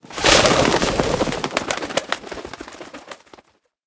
birdsfear3.ogg